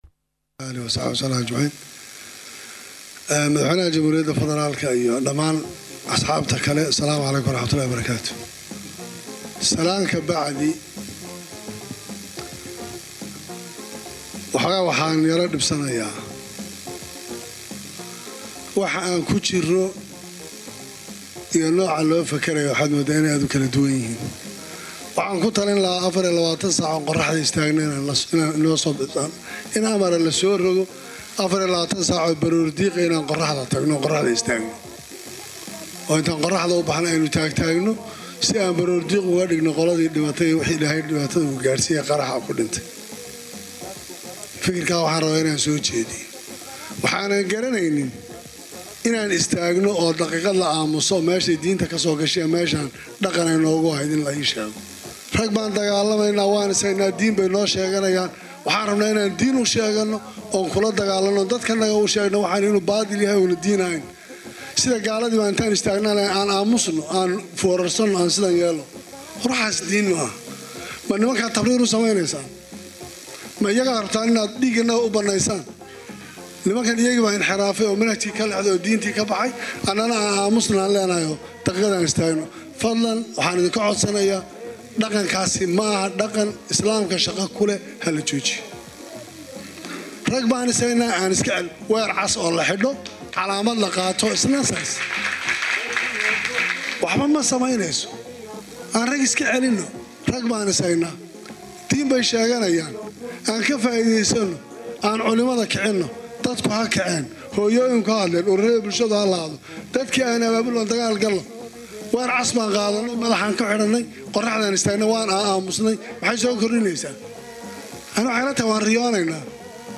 Axmed Maxamed Islaam “Axmed Madoobe” Madaxweynaha Maamulka Jubbaland oo ka hadlay Shirka ka socda magaalada Muqdisho ayaa madaxda Dowladda iyo kuwa Maamul Gobaleedyada ku dhaliilay sidii looga Jawaab celiyay Weeraradii Xanuunka Badnaa oo ay Al-Shabaab bishaan Gudaheeda ku qaadeen Isgoyska Zoobe iyo Hotel-ka Naasa-hablood ee degmada Xamar-weye.
Hoos ka dhageyso Codka Axmed Madoobe